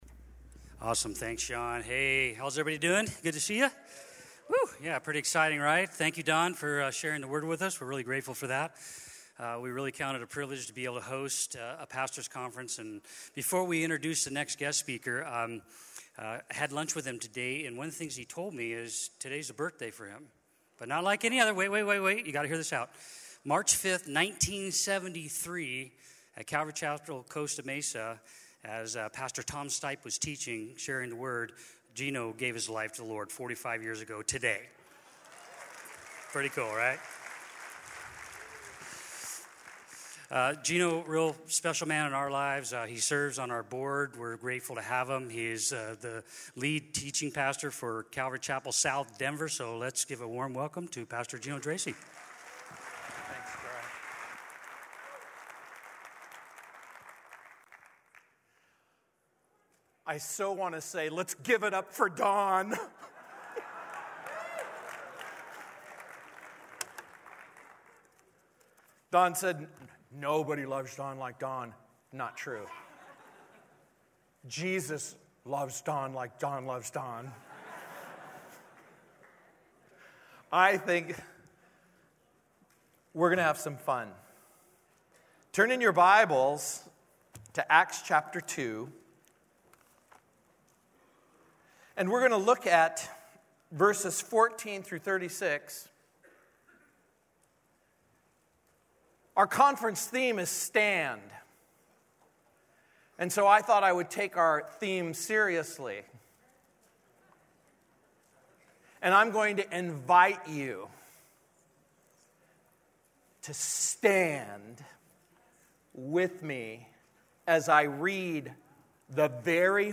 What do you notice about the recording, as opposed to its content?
at the 2018 Southwest Pastors and Leaders Conference